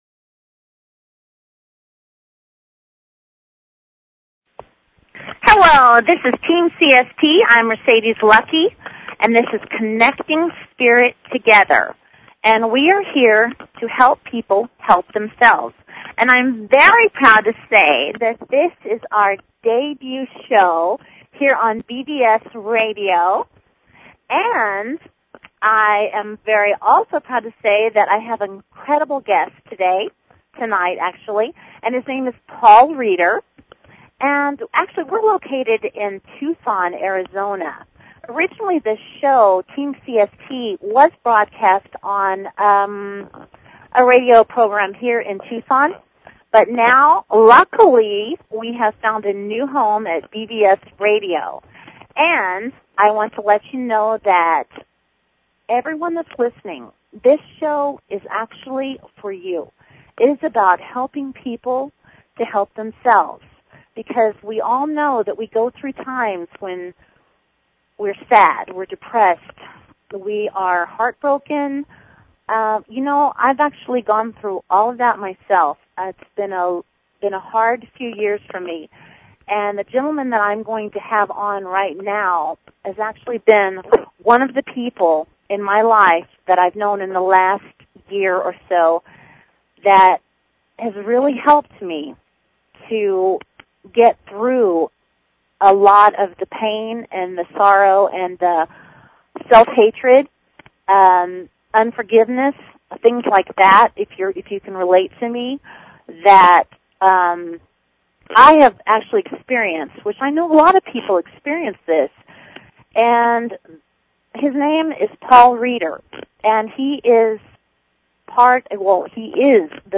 Talk Show Episode, Audio Podcast, Connecting_Spirit_Together and Courtesy of BBS Radio on , show guests , about , categorized as